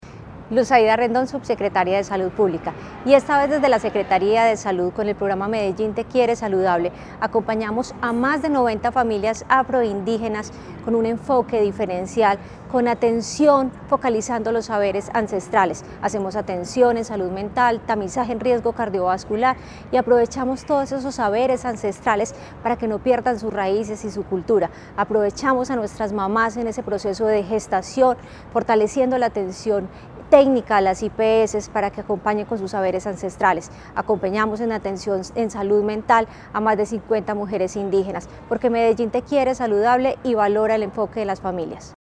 Audio Declaraciones de la subsecretaria de Salud Pública, Luz Aida Rendón El Distrito avanza en la implementación de acciones con enfoque diferencial étnico.